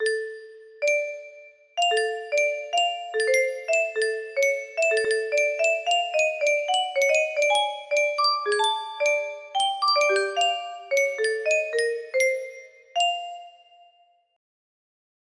idk I'm just experamenting but in my opinion, better music box melody